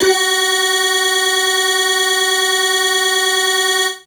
55bg-syn17-f#4.wav